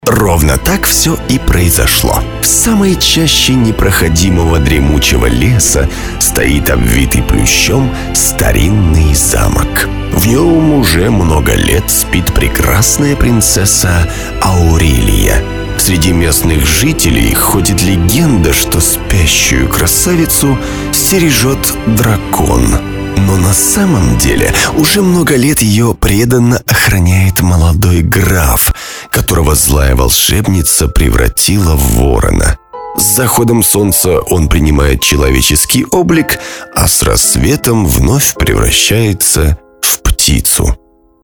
Особенно хорошо удаются игровые, сказочные персонажи, сказочники, голоса за кадром, рассказчики и т.д.
Rft Veb Mikrofontechnik Gefell – M-Audio FireWire 410 – Pop Filter Rode